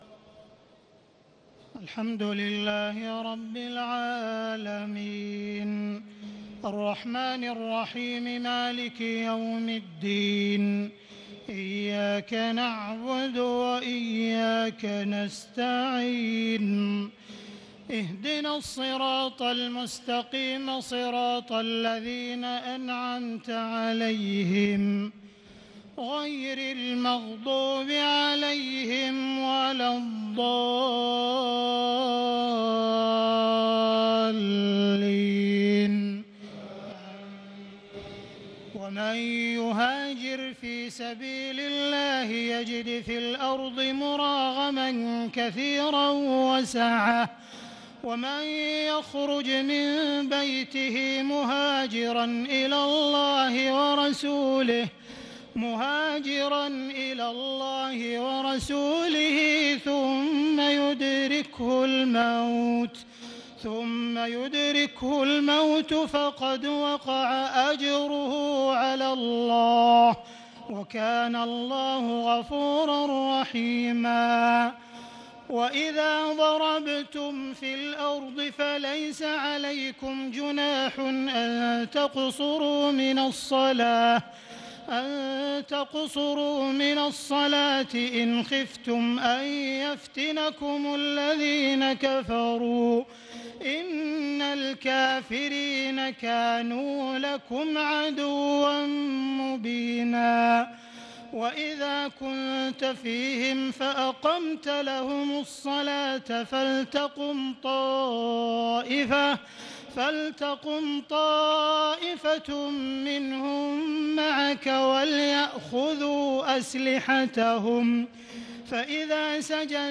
تهجد ليلة 25 رمضان 1438هـ من سورة النساء (100-147) Tahajjud 25 st night Ramadan 1438H from Surah An-Nisaa > تراويح الحرم المكي عام 1438 🕋 > التراويح - تلاوات الحرمين